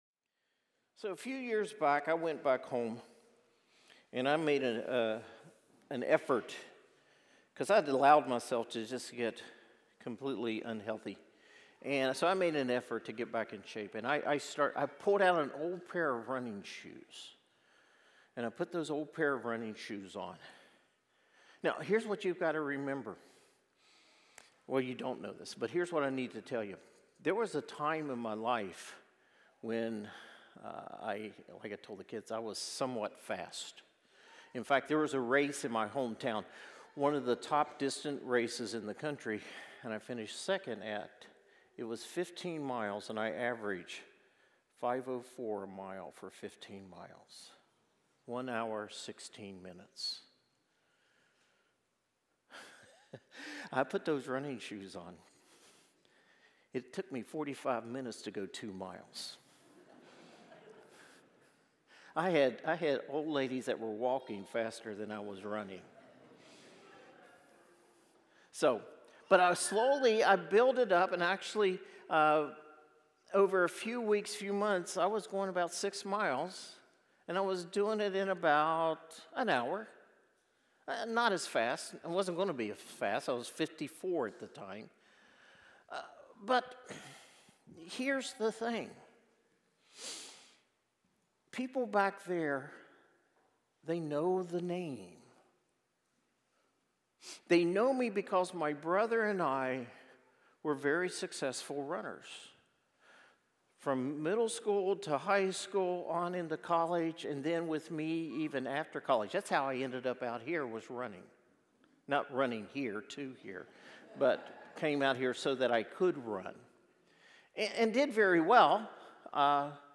Sermons | Salt Creek Baptist Church